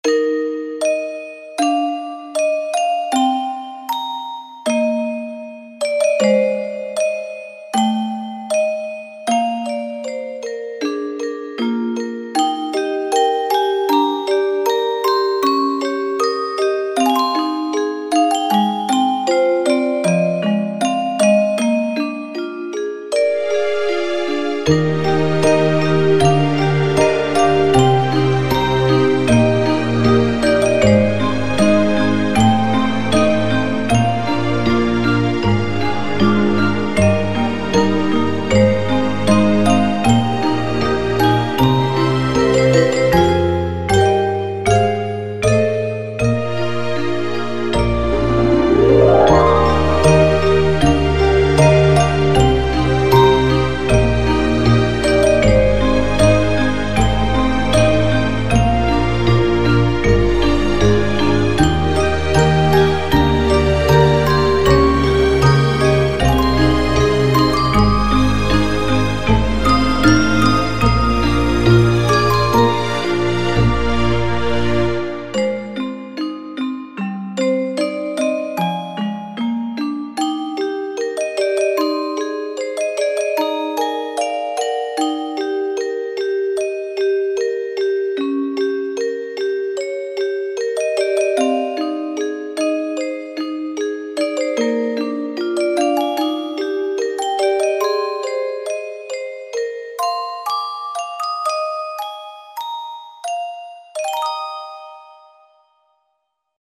ogg(R) 幻想的 かわいい オルゴール
オルゴール協奏曲。